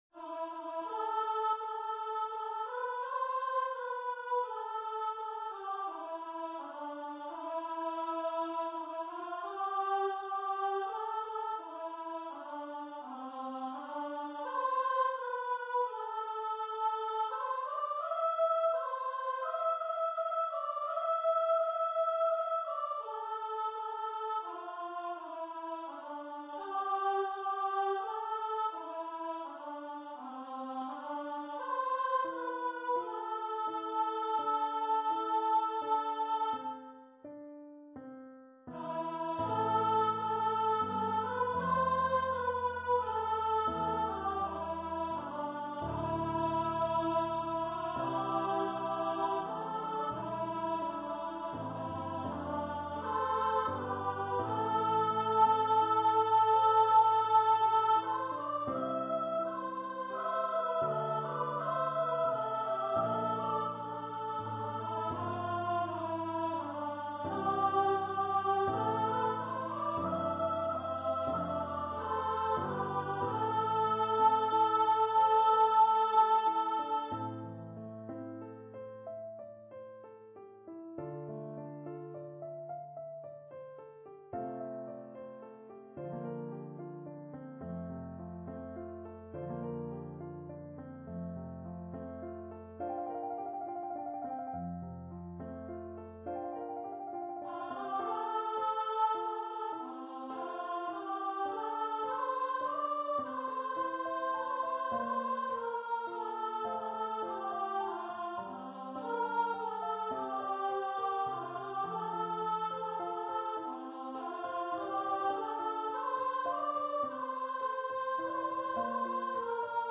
for female voice choir and piano
Choir - 3 part upper voices